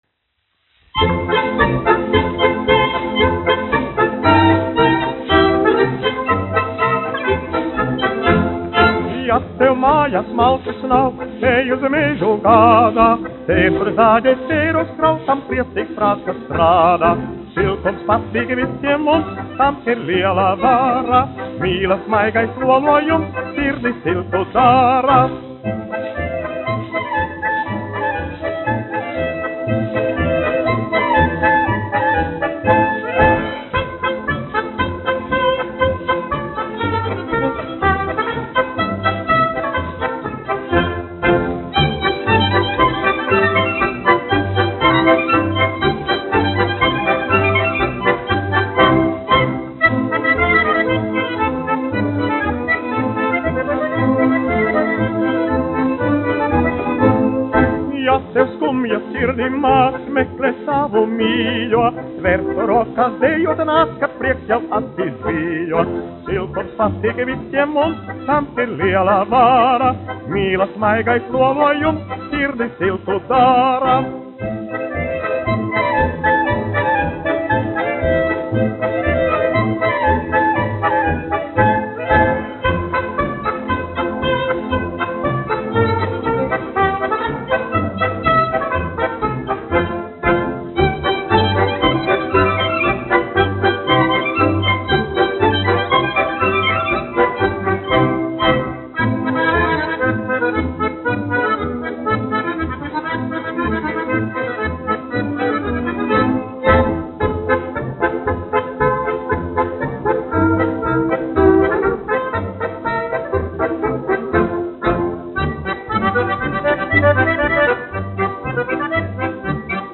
1 skpl. : analogs, 78 apgr/min, mono ; 25 cm
Polkas
Populārā mūzika -- Latvija
Skaņuplate